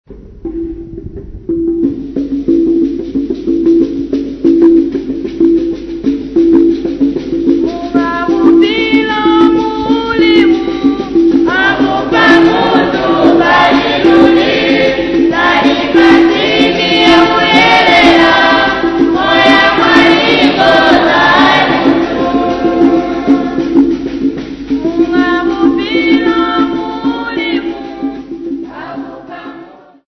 Choir
Folk music
Africa Namibia Katima Mulilo f-sx
field recordings
Church Hymn, unaccompanied.